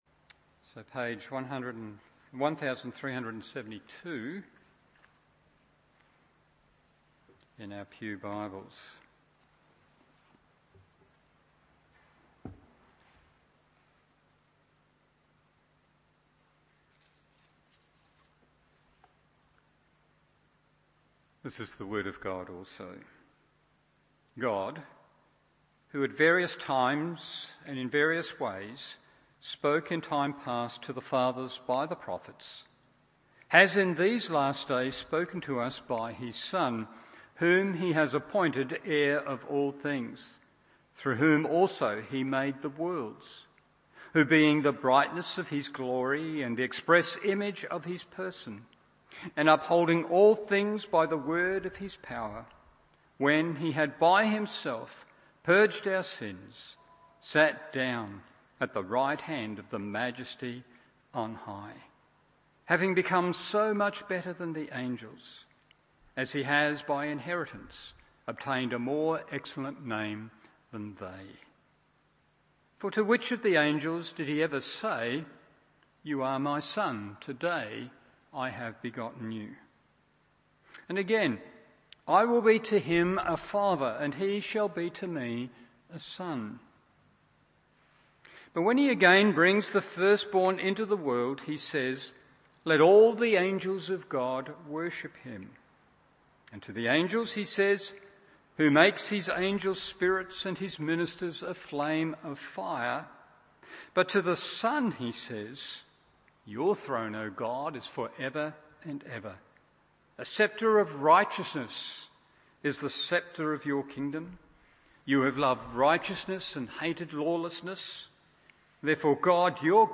Evening Service Hebrews 1:3 1. He reveals God to us 2. He serves God for us 3. He restores to to God…